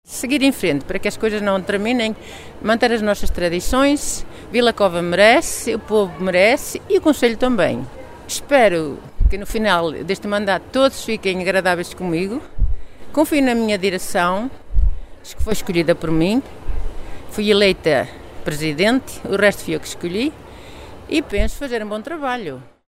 em declarações à Alive FM